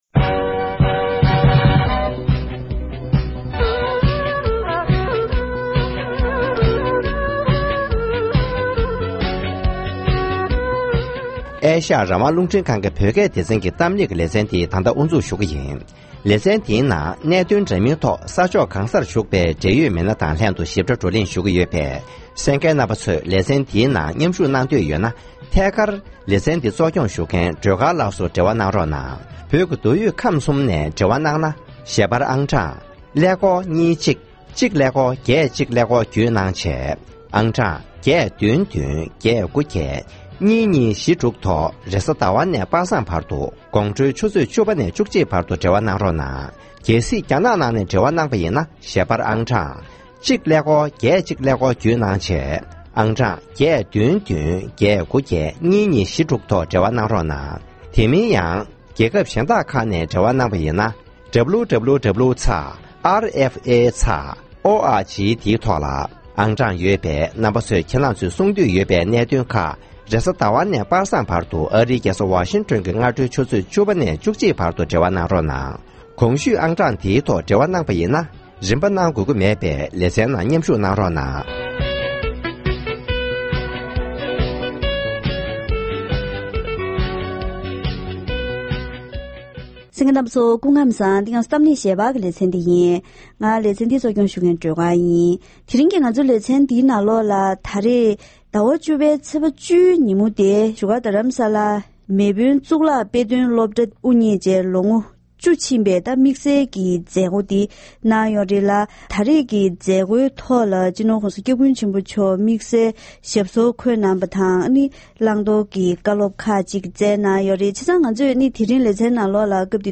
༄༅། །ཐེངས་འདིའི་གཏམ་གླེང་ཞལ་པར་ལེ་ཚན་ནང་བཞུགས་སྒར་རྡ་རམ་ས་ལར་ཡོད་པའི་མེས་དབོན་གཙུག་ལག་དཔེ་སྟོན་སློབ་གྲྭ་དབུ་བརྙེས་ནས་ལོ་ངོ་བཅུ་ཕྱིན་པའི་མཛད་སྒོའི་ཐོག་སྤྱི་ནོར་༧གོང་ས་༧སྐྱབས་མགོན་ཆེན་པོ་མཆོག་ནས་ད་ལྟ་བར་བོད་མི་རིགས་འབུར་ཐོན་ངང་གནས་ཐུབ་པ་འདི་བོད་སྐད་ཡིག་ཐོག་ནས་ཡིན་པ་སོགས་བཀའ་སློབ་གནང་བ་དང་། དེ་བཞིན་དཔེ་སྟོན་སློབ་གྲྭ་ངོ་སྤྲོད་ཞུས་པ་ཞིག་གསན་རོགས་གནང་།